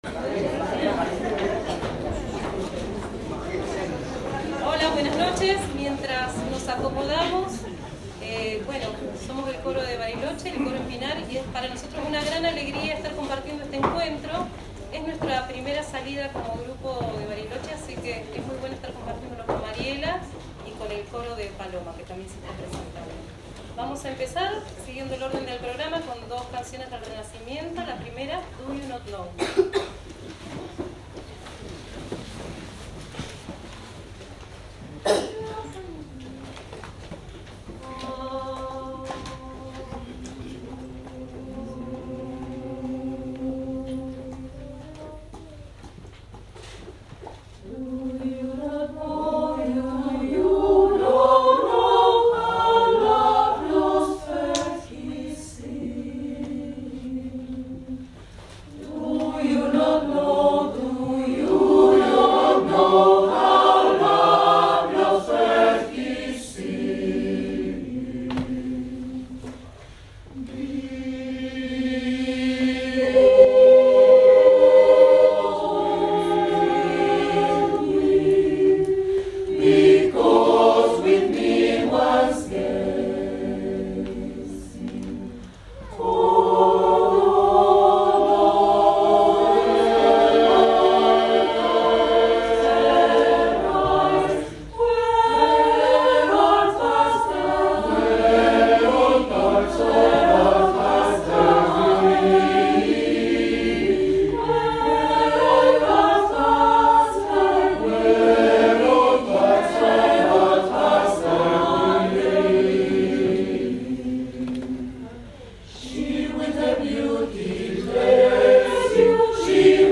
Cantamos en el TEATRO SAN JOSÉ